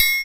percussion 41.wav